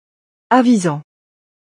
Avisant means advising in French. Listen to the pronunciation by clicking the play button below…
avisant_fr.wav